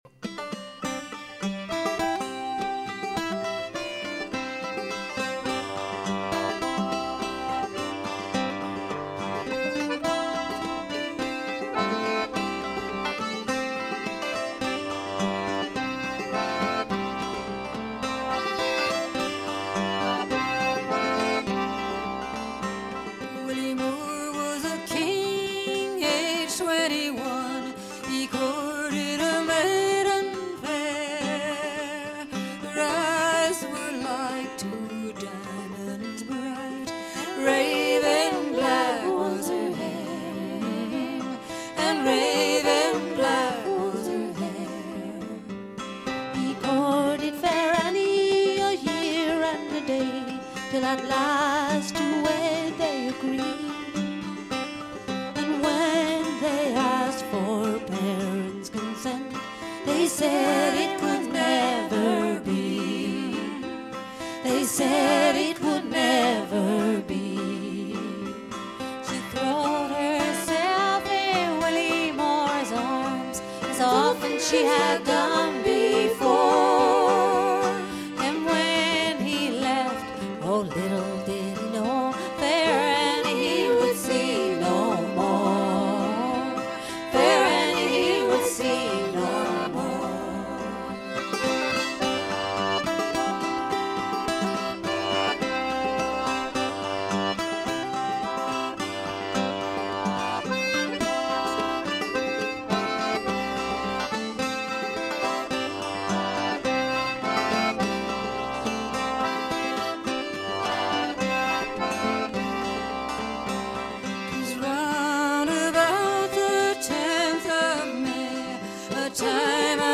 【民谣天后】
您将听到重唱 男女对唱 当然还有 她本人那迷死人的自弹唱